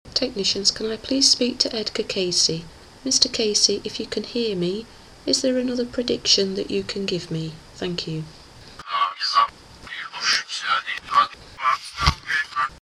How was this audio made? (Sony B300 and reversed Latvian background file)